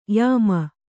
♪ yāma